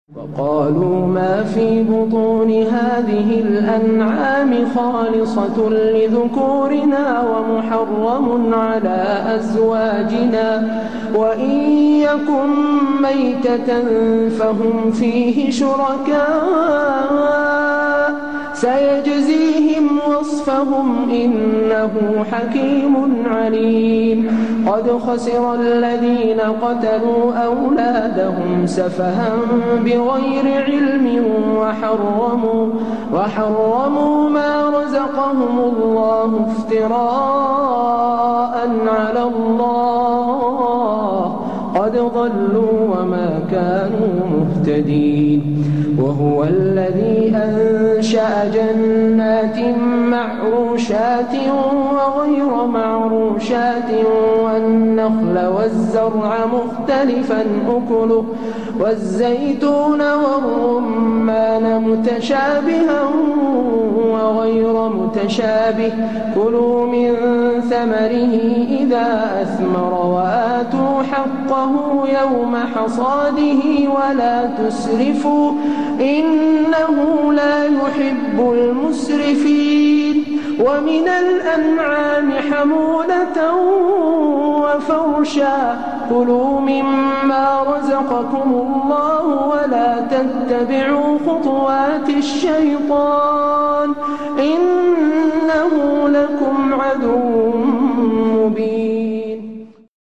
من هو هذا القارئ :